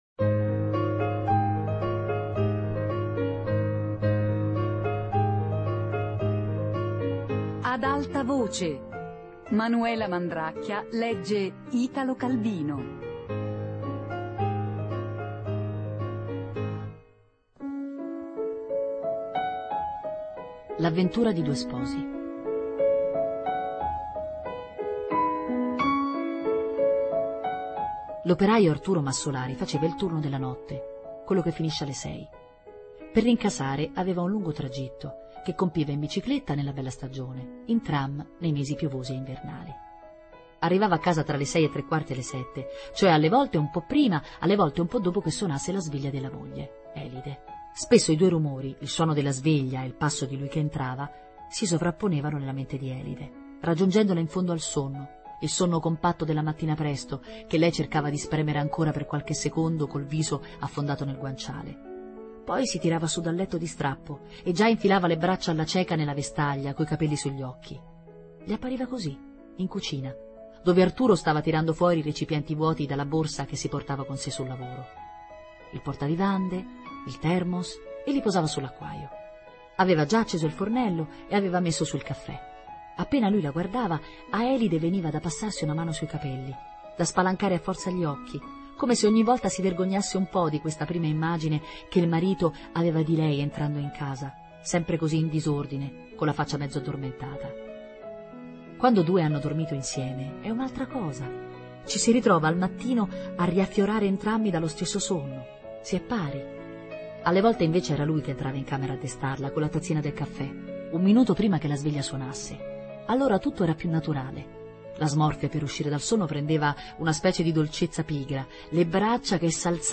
L'avventura di un poeta RIASCOLTA Italo Calvino - Lettura I Italo Calvino - Lettura II Italo Calvino - Lettura III Italo Calvino - Lettura IV Italo Calvino - Lettura V RADIO 3